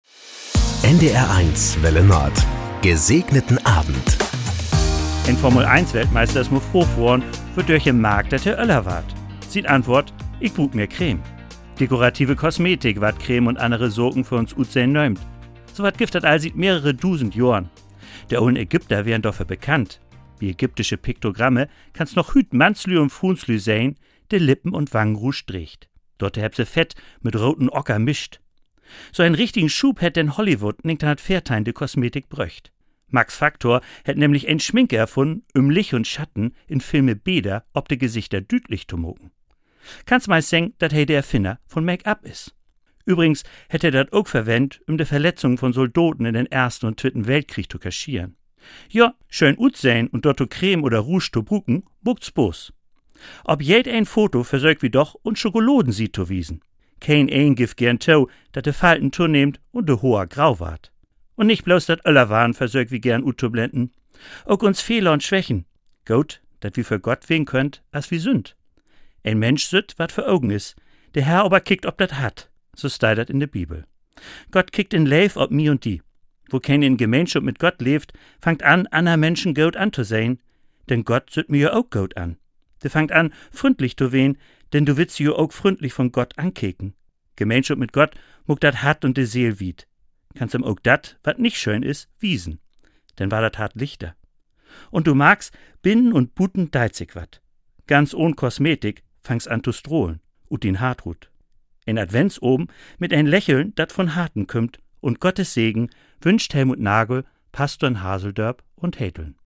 Das gute Wort zum Feierabend auf NDR 1 Welle Nord mit den Wünschen für einen "Gesegneten Abend".